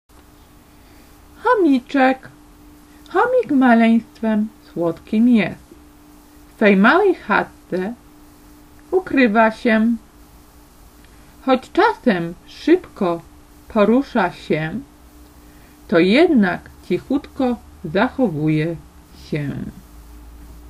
Audiobook z wierszykiem dla dzieci"Chomiczek"Słuchaj